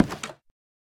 Minecraft Version Minecraft Version 1.21.5 Latest Release | Latest Snapshot 1.21.5 / assets / minecraft / sounds / block / nether_wood_door / toggle2.ogg Compare With Compare With Latest Release | Latest Snapshot